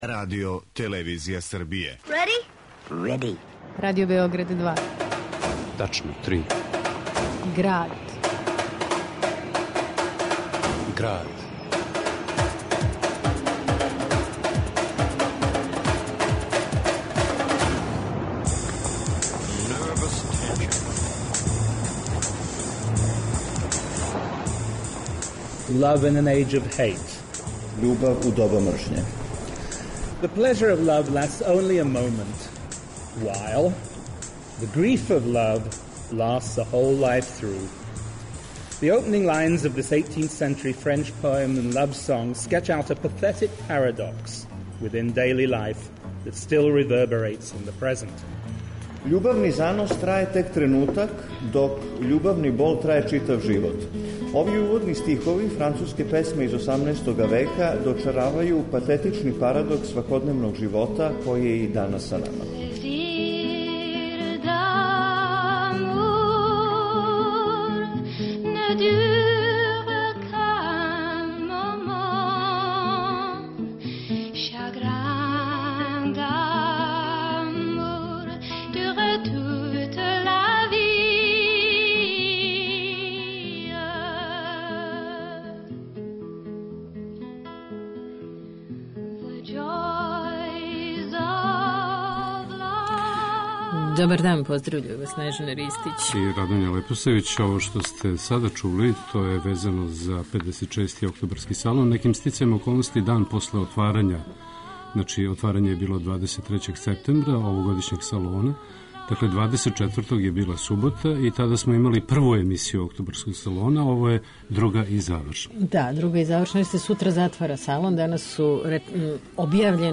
У Граду , уз документарне снимке, о 56.